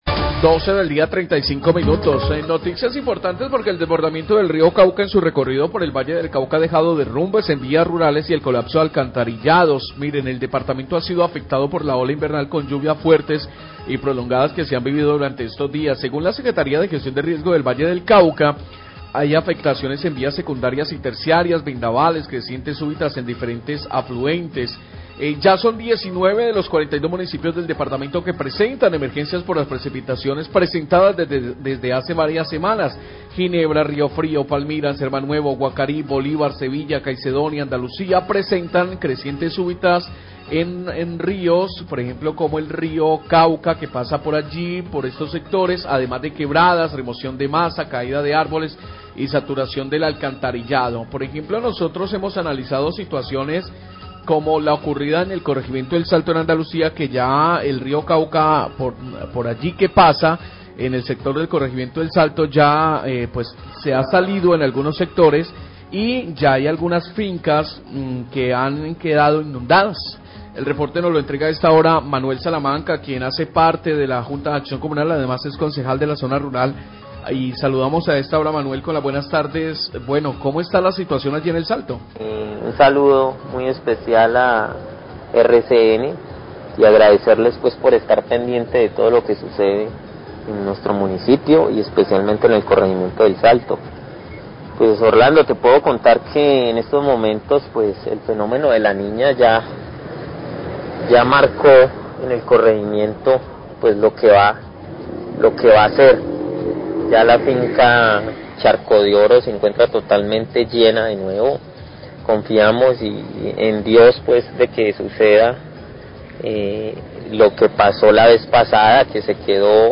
Líder social El Charco habla de afectaciones por inundaciones del Río Cauca y construcción jarillón
Radio